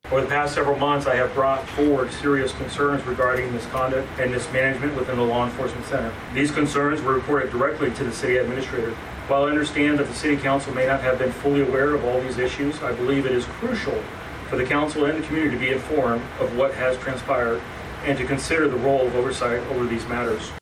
Humboldt, IA – A packed chamber at Monday’s City Council session in Humboldt heard allegations of workplace misconduct within the Humboldt Police Department.